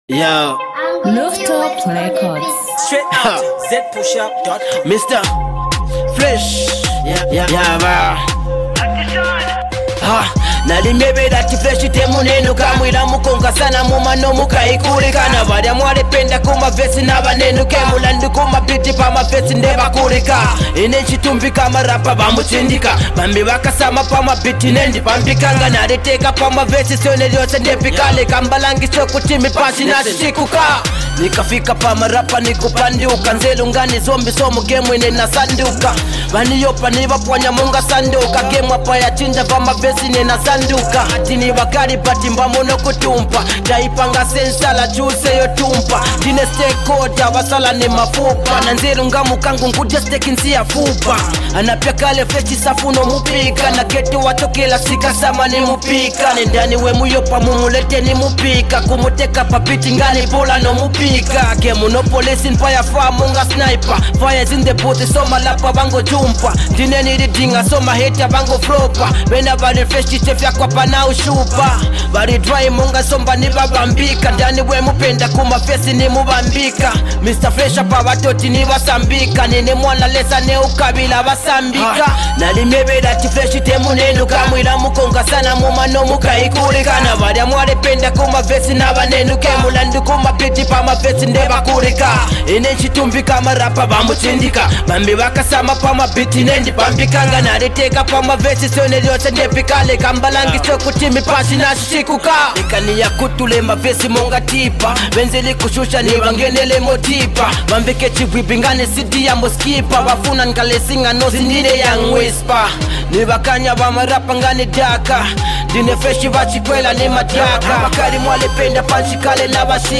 MUSIC
went hard putting out all his skilful rap tactics.